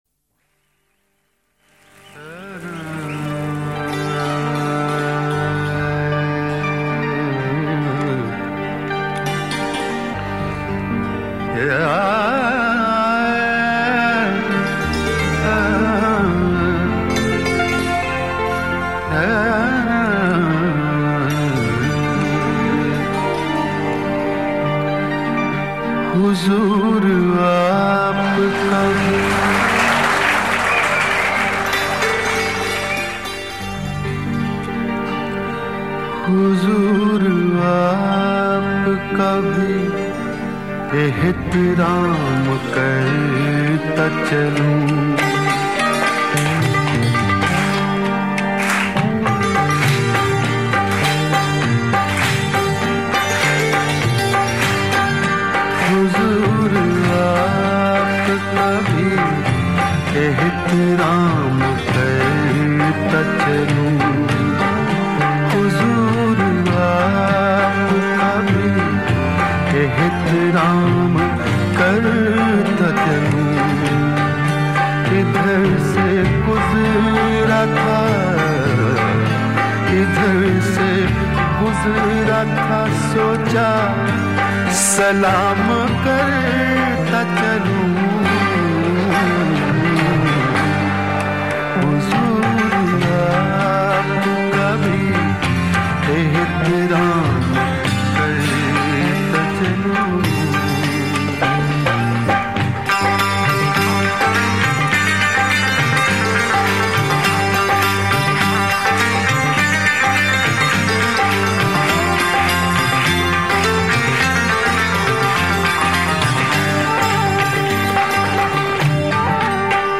Ghazals